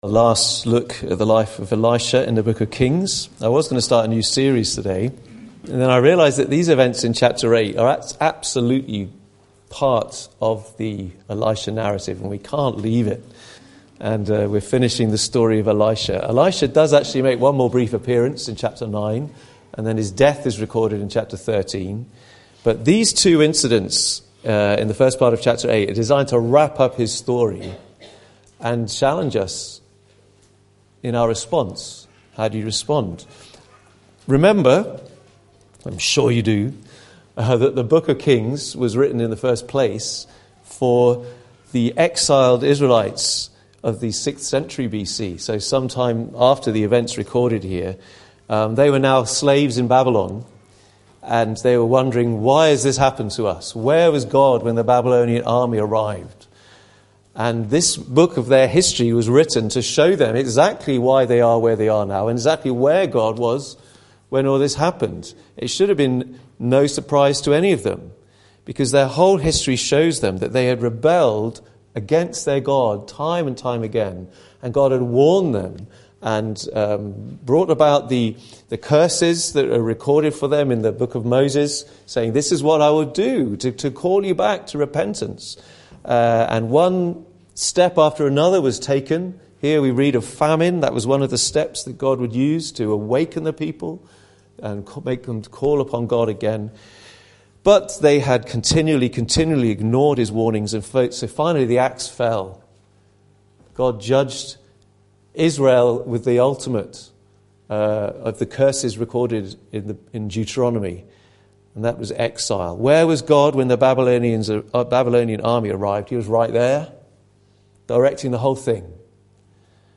Life & Times of Elisha Passage: 2 Kings 8:1-15 Service Type: Sunday Morning « Jude’s Guide to Identifying False Teachers Are You Listening to God?